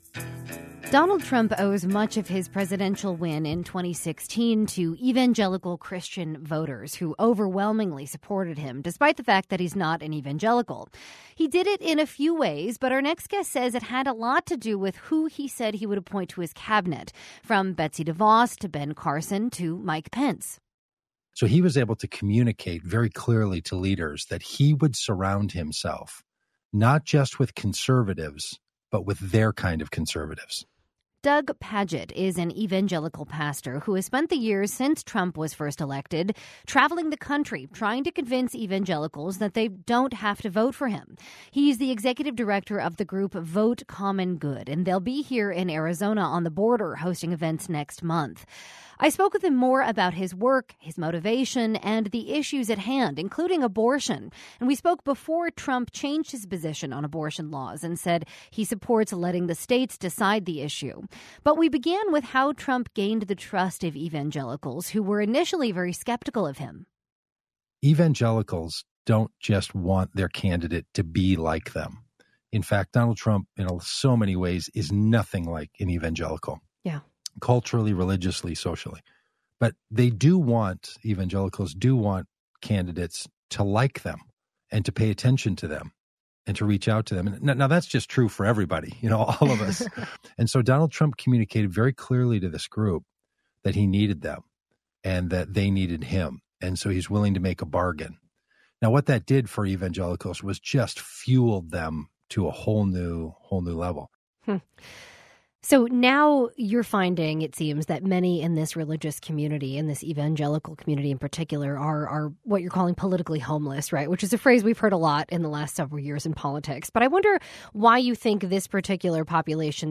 Read Full Interview